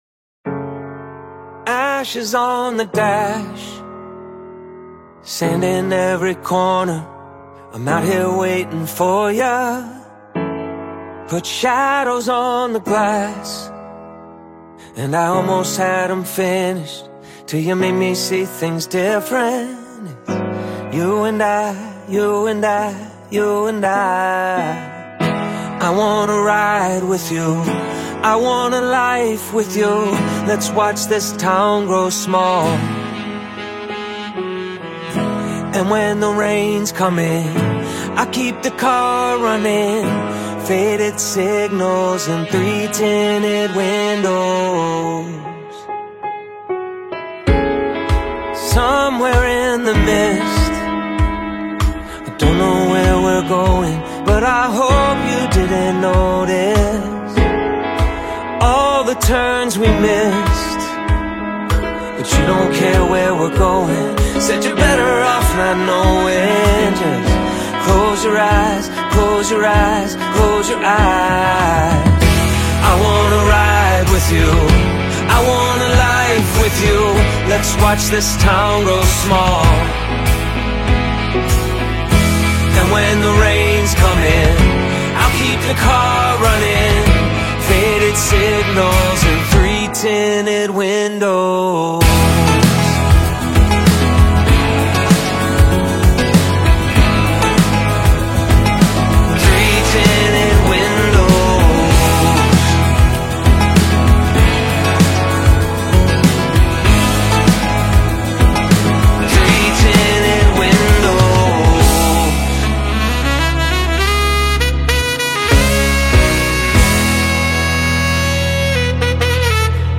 smooth, laid-back groove